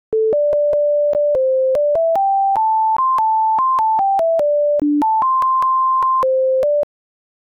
Hair thickness is proportional to the number of different patterns in a tune, and these tunes have, relatively speaking, a small number of different patterns, largely because they lack tied notes and large intervals, features common to many folk tunes.